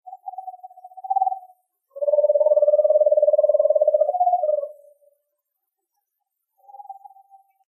Megascops asio
Screech-Owl, Eastern
Screech-Owl_Eastern.oga